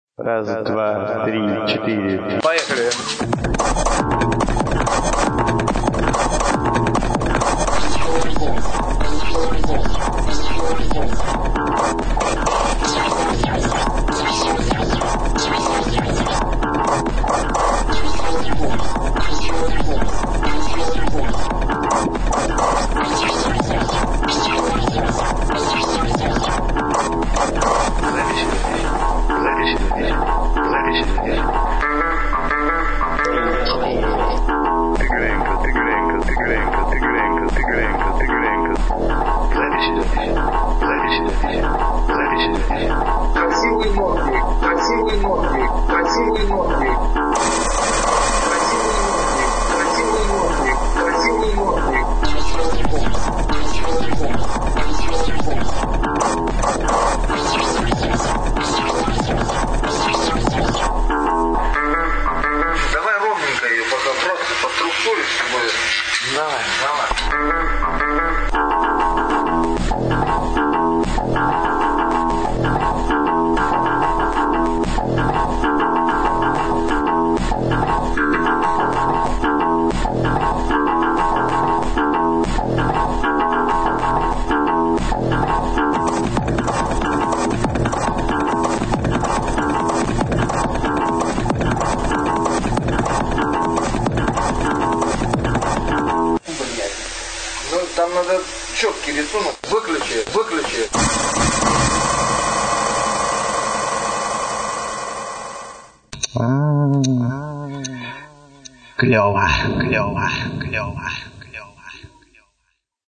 Зато свершилось - мой первый МИКС готов!
Слова разобрать можно, но не везде...